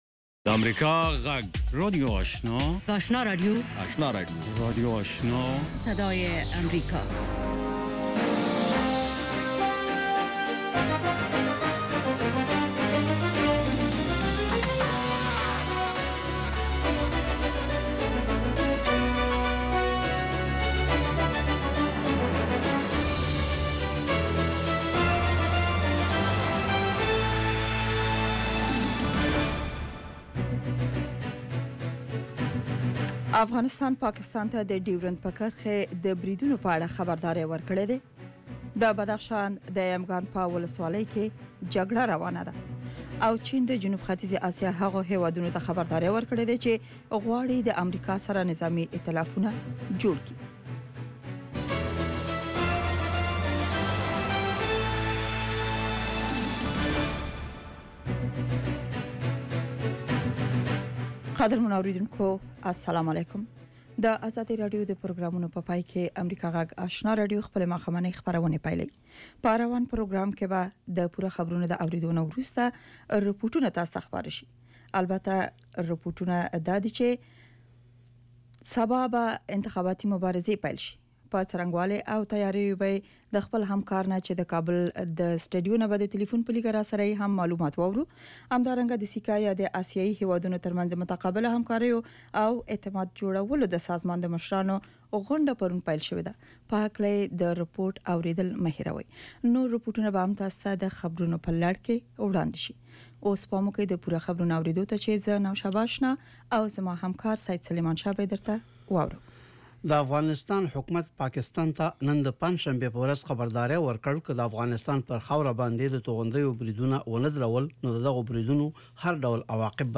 ماښامنۍ خبري خپرونه
یو ساعته پروگرام: د ورځې د مهمو سیاسي، اجتماعي او نورو مسایلو په اړه له افغان چارواکو او کارپوهانو سره خبرې کیږي. د اوریدونکو پوښتنو ته ځوابونه ویل کیږي. ددغه پروگرام په لومړیو ١٠ دقیقو کې د افغانستان او نړۍ وروستي خبرونه اورئ.